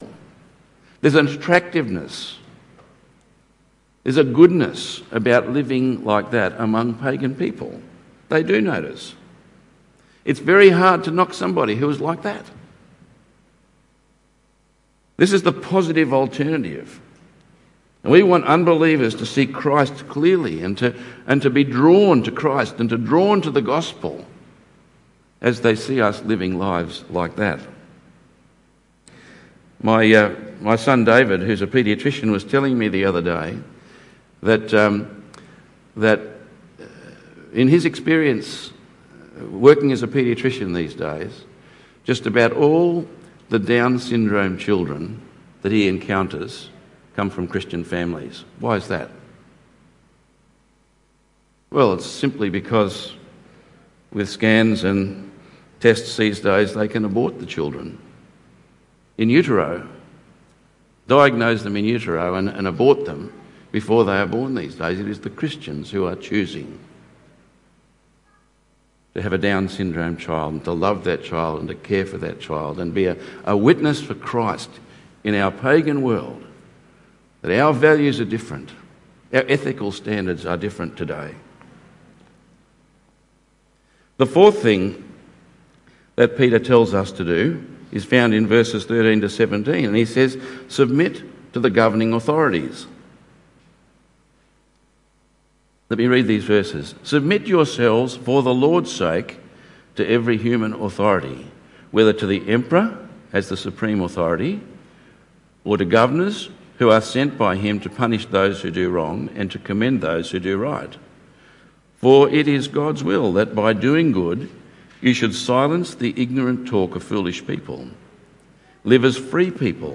In today’s message we see some of the practical ways we can maintain our distinctiveness, without becoming obnoxious, and hopefully commending the gospel and bringing glory to God. 1 Peter 2:11-25 Tagged with Sunday Morning